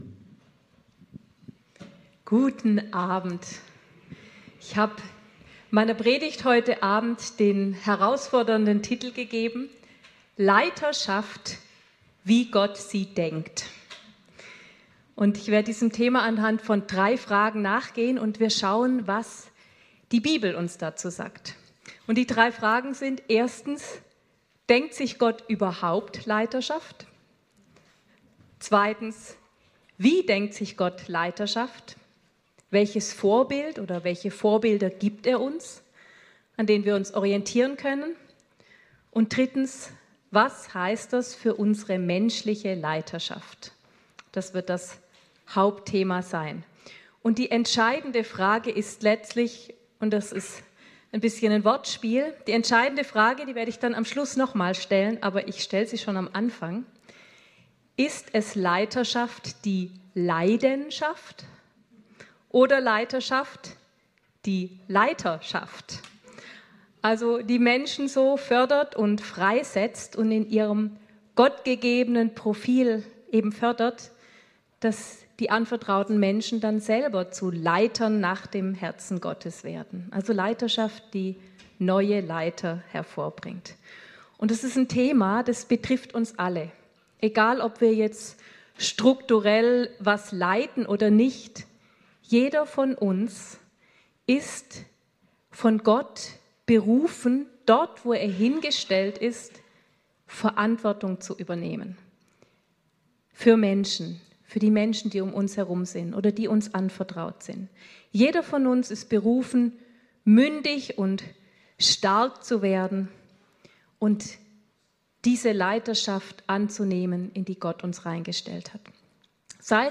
Dienstagsgottesdienst vom 17.